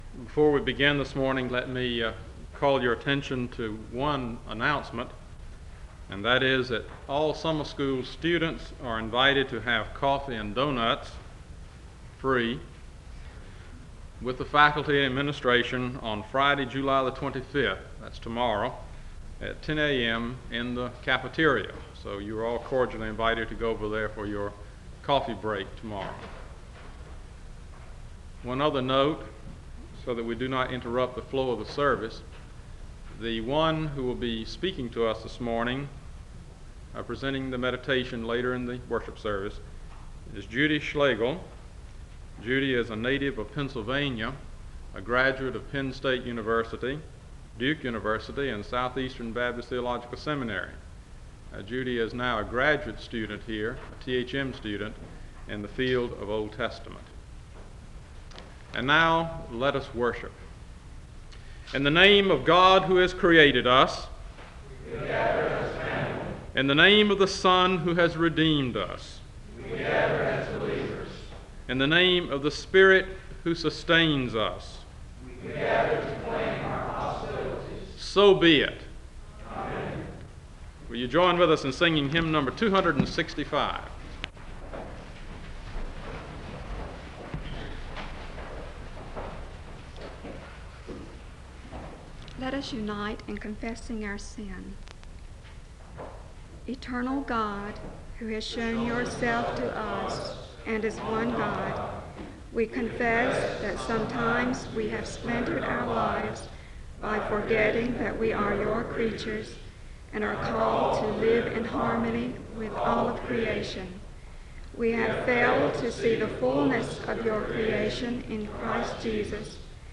The service begins with announcements and a benediction (00:00-01:24).